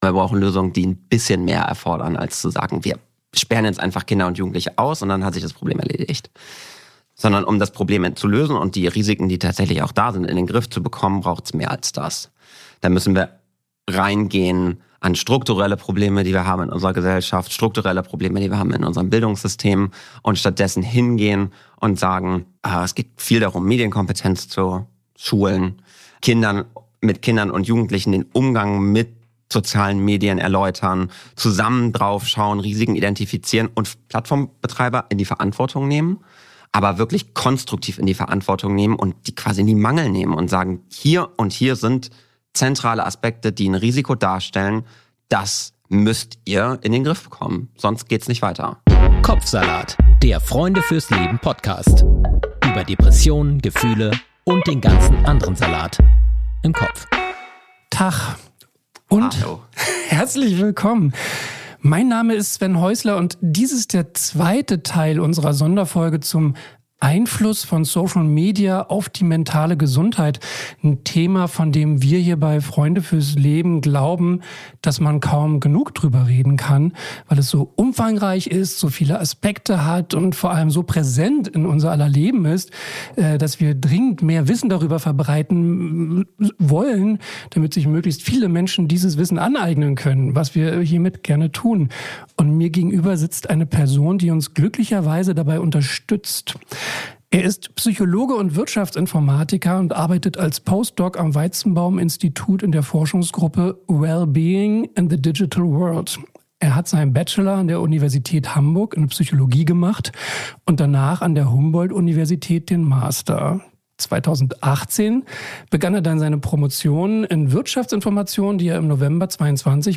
Musik und Jingle